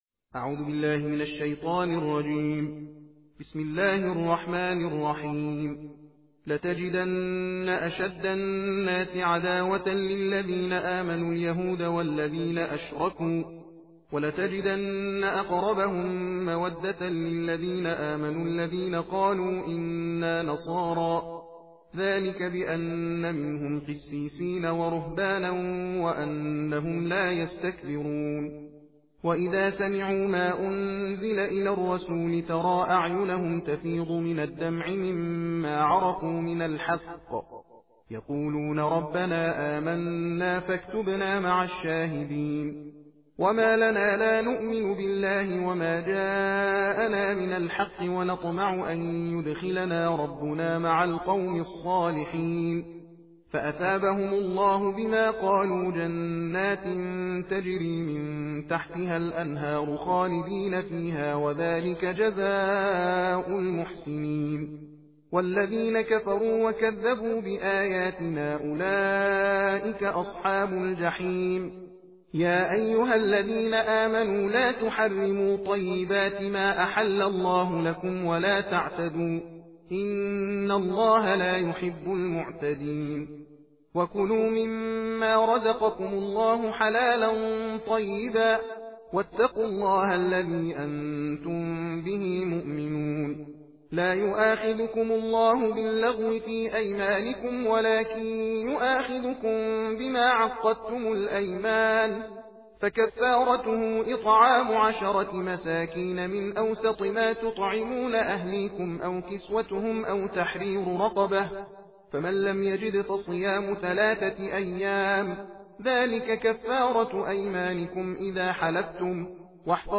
جزء هفتم قرآن صوتی تندخوانی روان+ متن عربی درشت با ترجمه
در این مطلب از مجله ستاره به جزء ۷ قران صوتی تند خوانی گوش فرا دهید و متن عربی و ترجمه فارسی آن را مطالعه کنید.